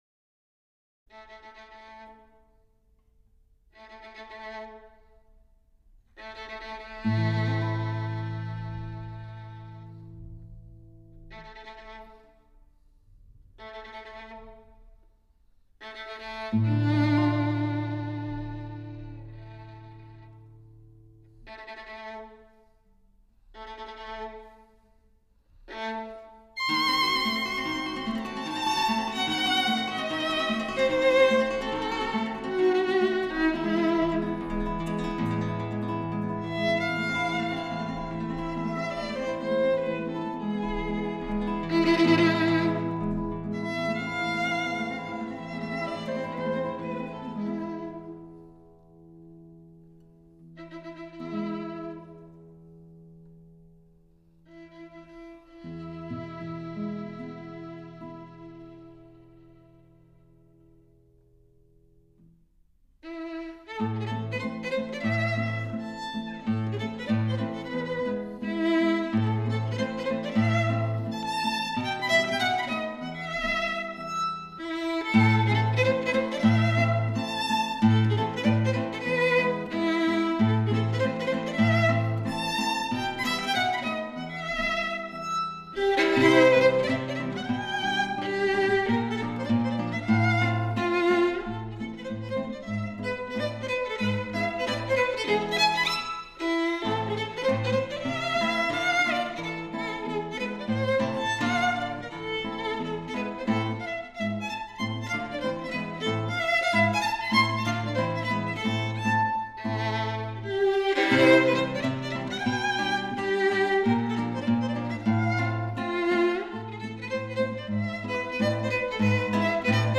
室内乐
美妙的小提琴音配上绝美的旋律，无论当背景音乐聆听或是认真欣赏，都可以感受到这些乐曲中优雅、华丽而高贵、深情的音乐魅力。